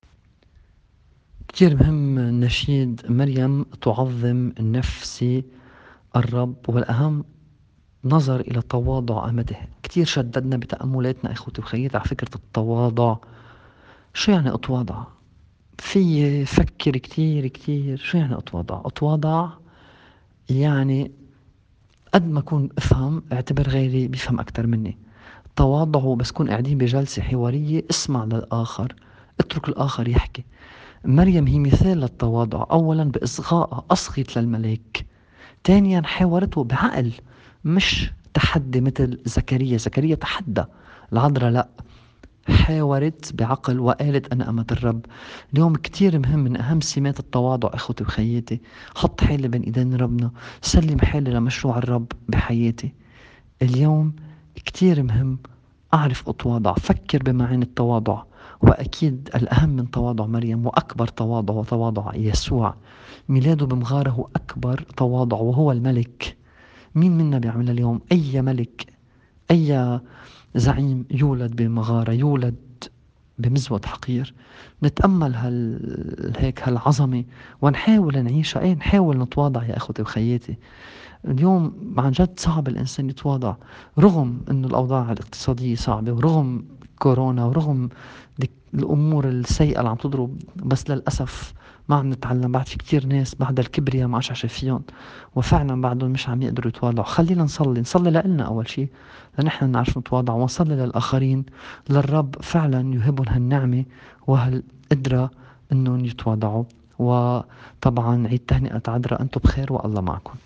تأمّل في إنجيل اليوم